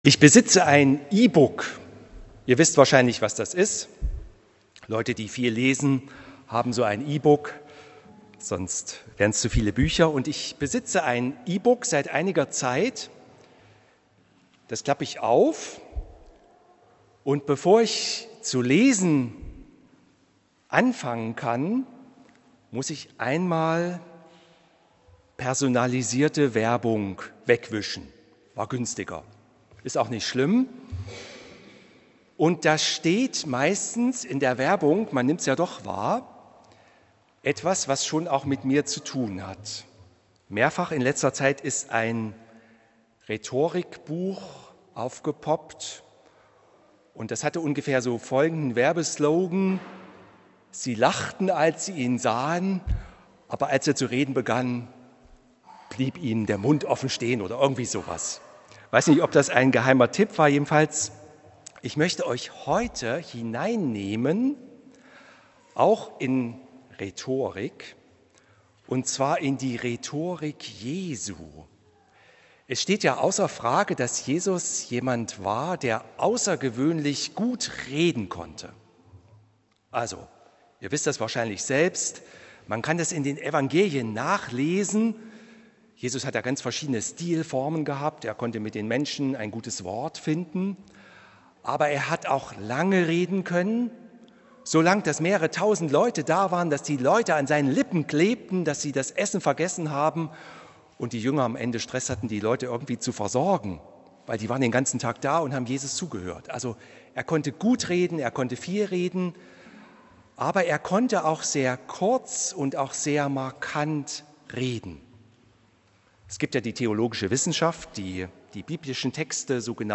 Hören Sie hier die Predigt zu Lukas 18,1-8